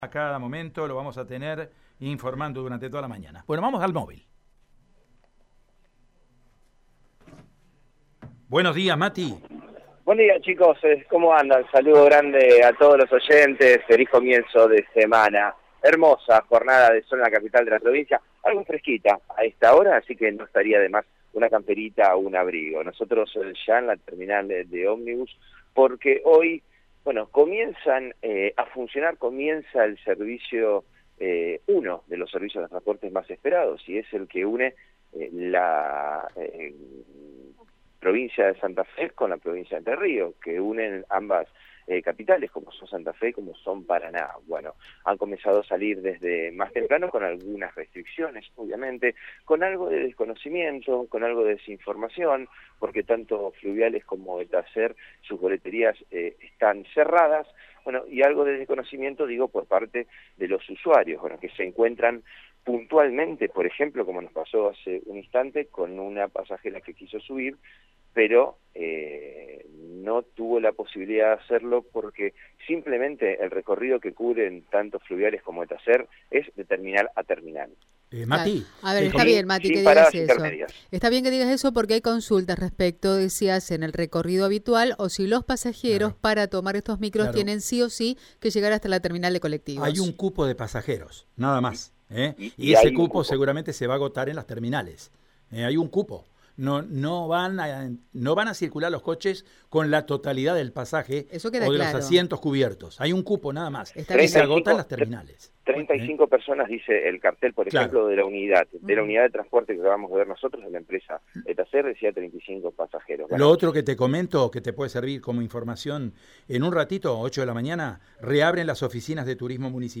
Desde el Centro de Información Turística, una de las empleadas recordó en dialogo con el móvil de Radio EME los requisitos que deben cumplir los pasajeros: «Se necesita el certificado de circulación que emite la página de Nación o desde la aplicación CUIDAR. El documento porque les van a pedir una declaración jurada. Y el uso barbijo obviamente».